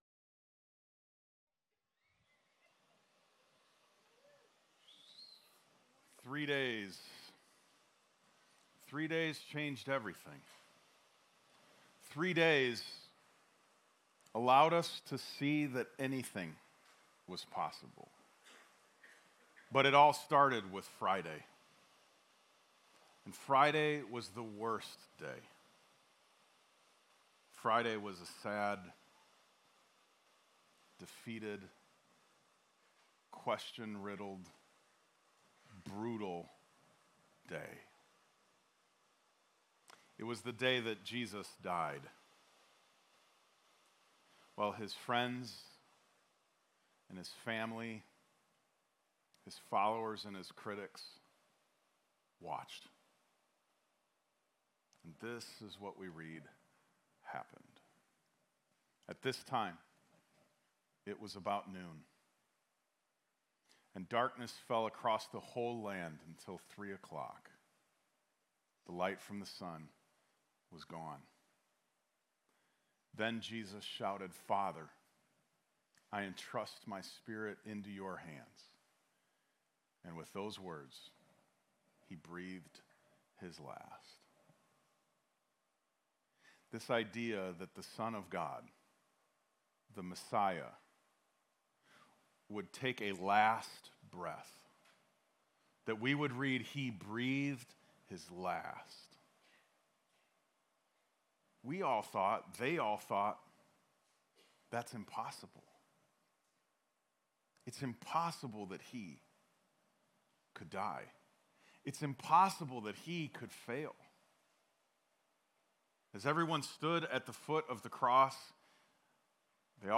Talk_Easter_Service__3_Da_1080.m4a